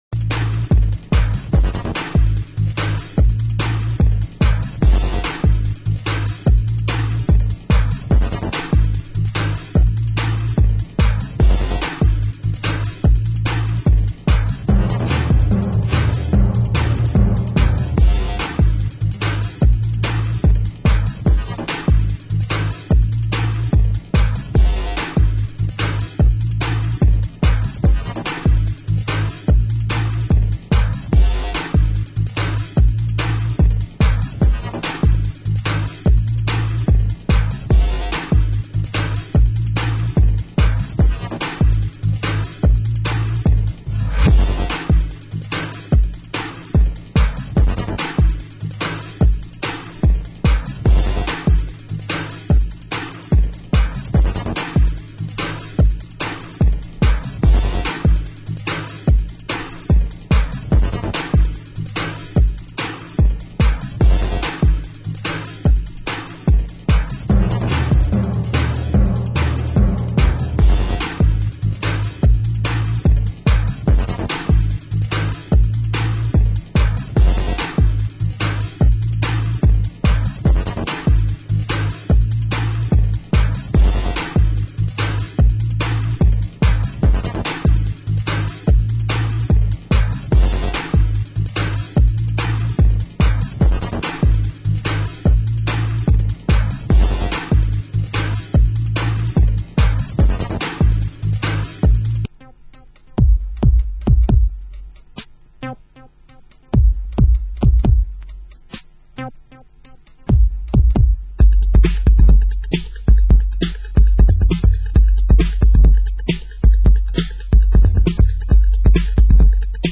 Genre: Ghetto Tech